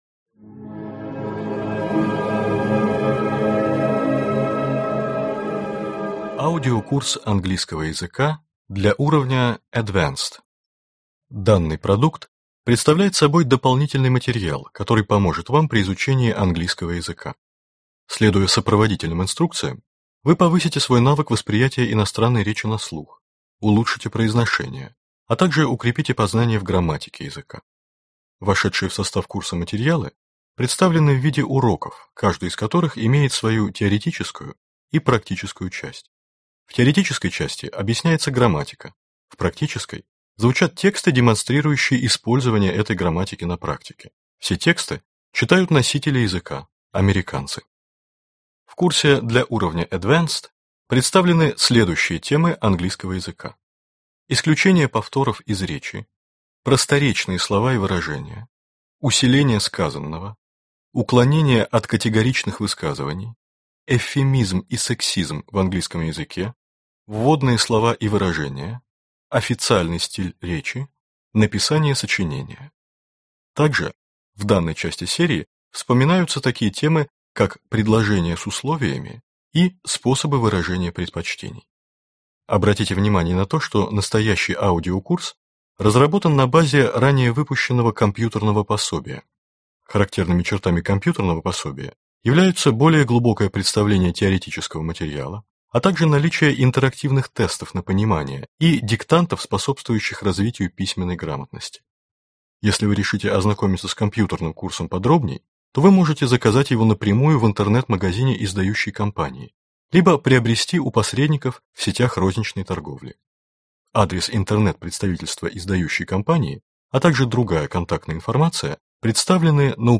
Курс уровня Advanced» Автор Илья Чудаков Читает аудиокнигу Актерский коллектив.